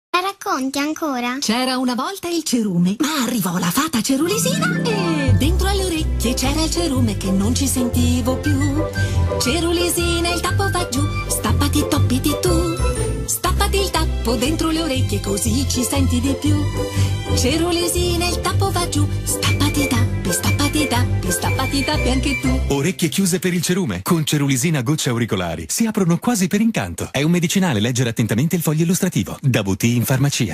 Vera e propria case history del settore, per la comunicazione di Cerulisina, prodotto destinato alla salute delle orecchie, l’Agenzia ha ideato e prodotto uno spot radio 30” che, sulla falsariga di una fiaba per bambini, racconta il prodotto in modo inedito.
Cerulisina-Spot.mp3